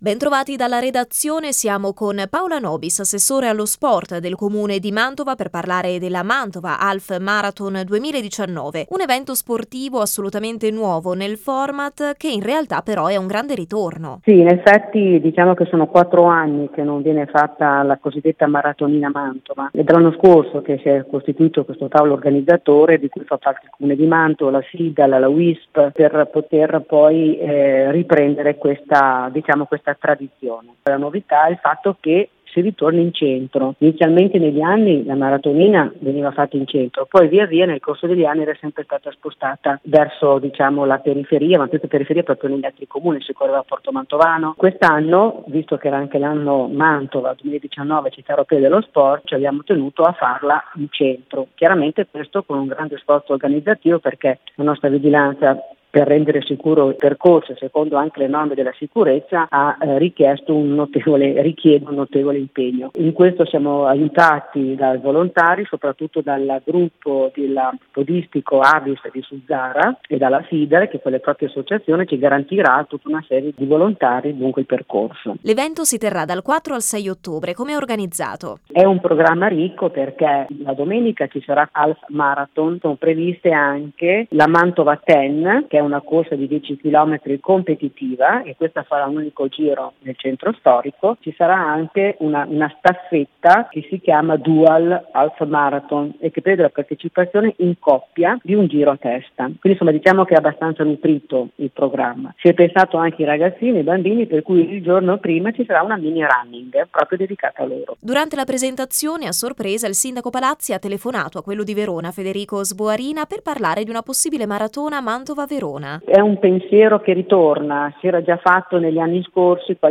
l’Assessore allo Sport del Comune di Mantova, Paola Nobis: